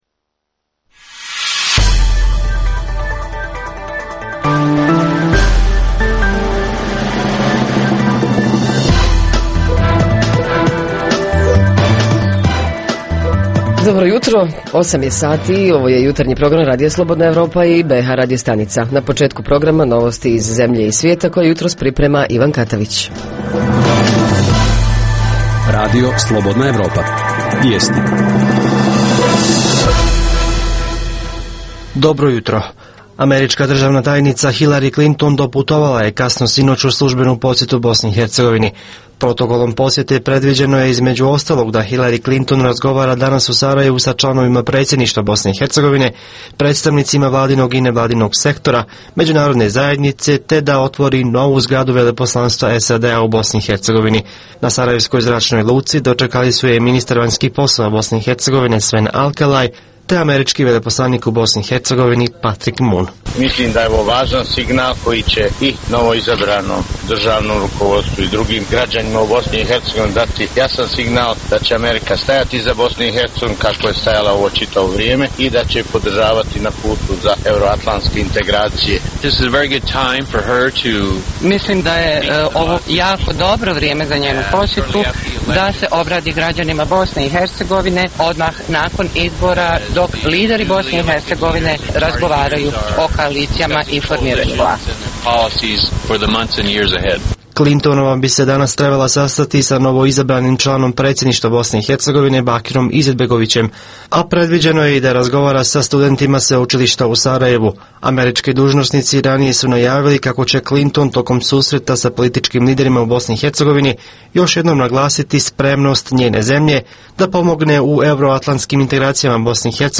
- Redovni sadržaji jutarnjeg programa za BiH su i vijesti i muzika.